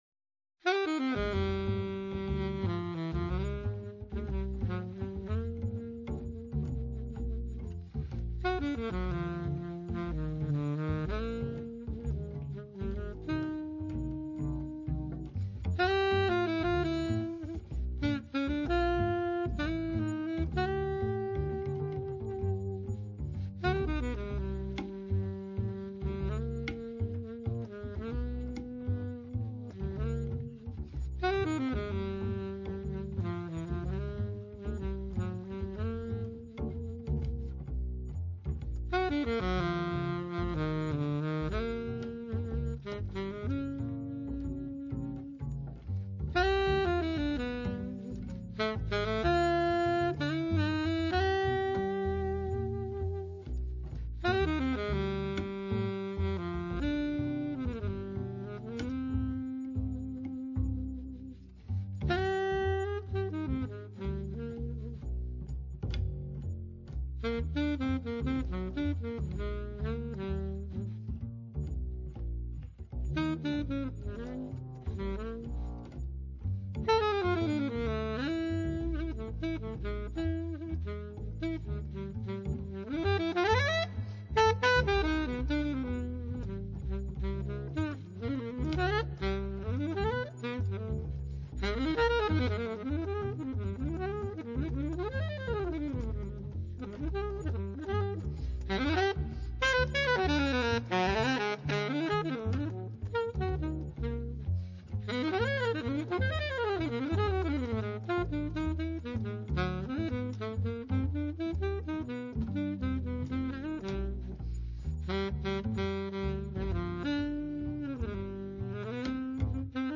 alto and sopranino saxophones
piano
bass
drums